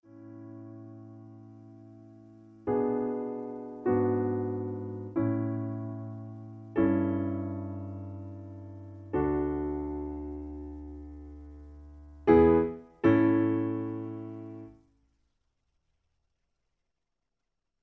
In A. Piano Accompaniment